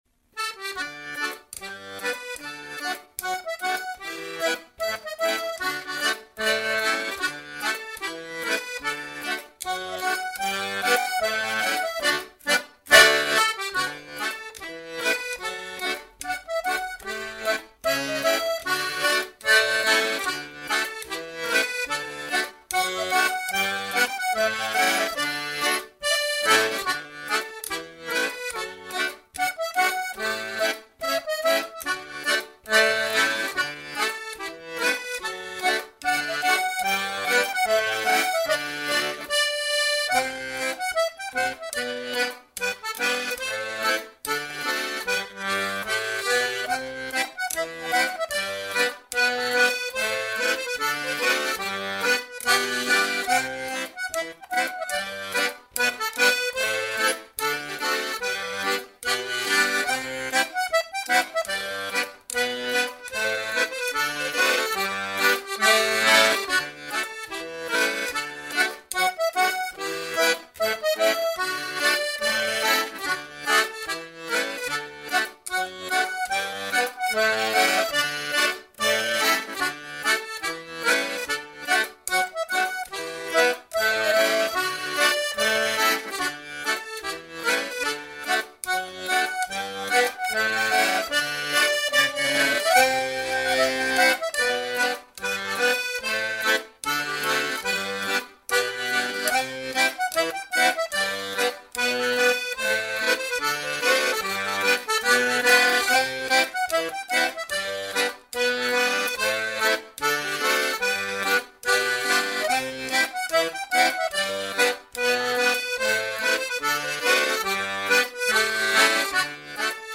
Clog Music 15% slower
Shunts 90bpm